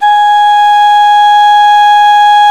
SULING G#4.wav